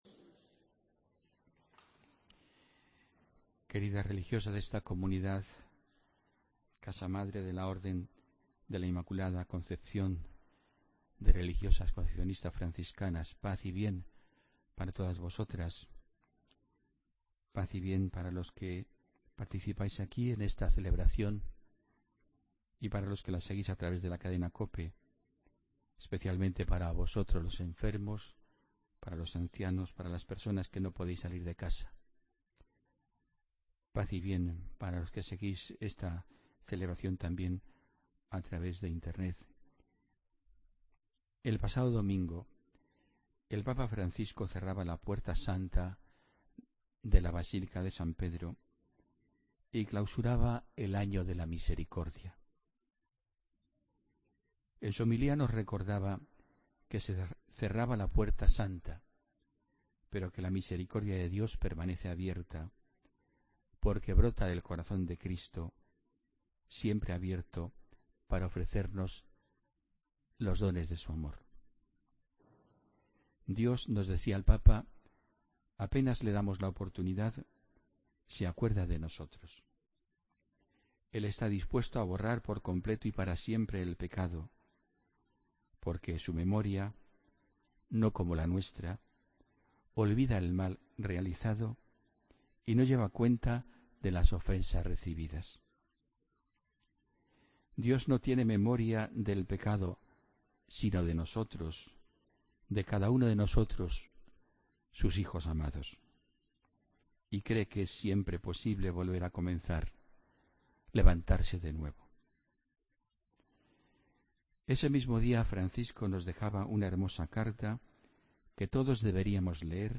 Homilía del domingo 27 de noviembre